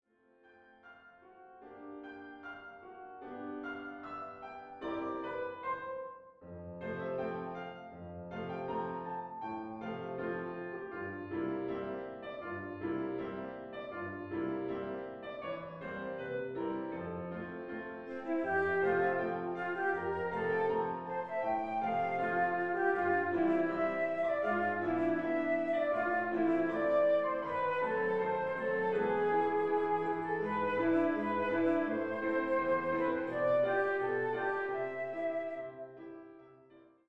Klavier-Sound mit Oboe als Sopranstimme (Koloratur gekürzt)